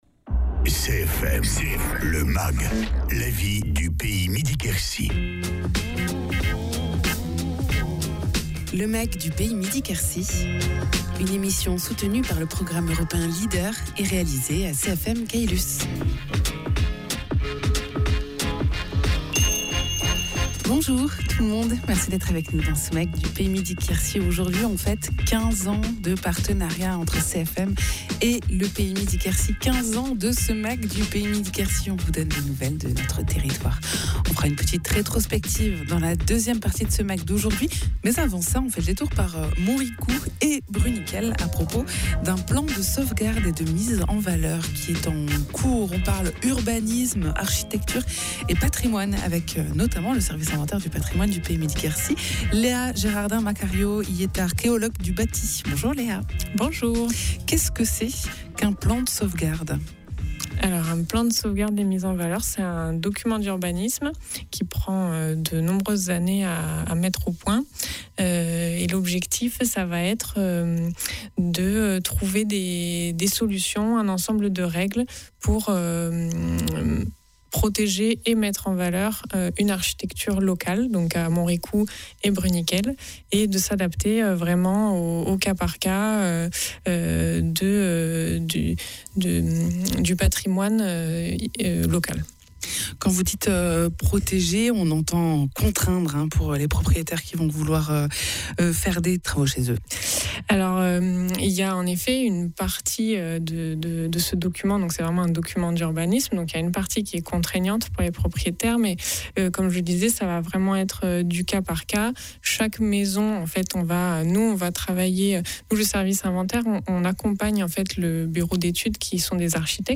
Un plan de sauvegarde et de mise en valeur est en cours d’élaboration sur Bruniquel et Montricoux, une étude complète du patrimoine bâti afin de construire des règles de protection et de rénovation au cas par cas. Egalement dans ce mag, l’anniversaire des 15 ans de partenariat entre le PETR Pays Midi Quercy et CFM radio : 15 ans d’objectifs et de valeurs communes mis en exergue notamment par ce magasine d’actualités locales hebdomadaire !